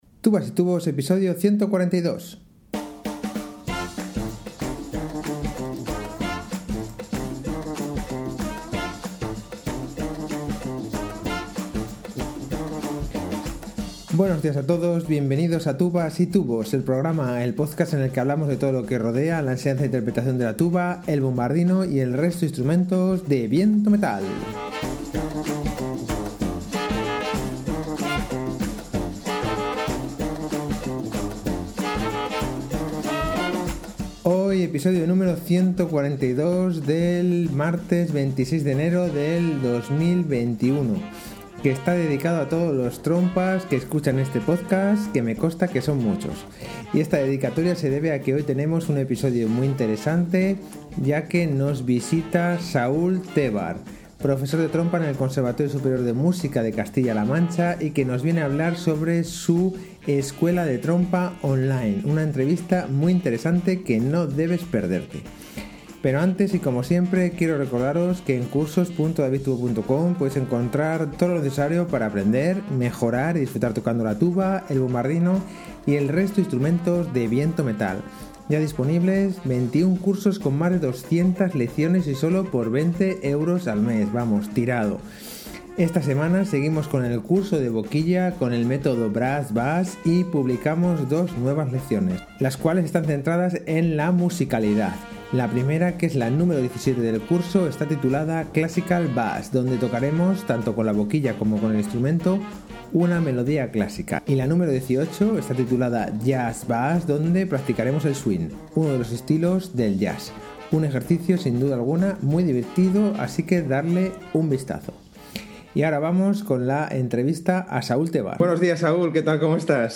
Una entrevista muy interesante que no debes perderte.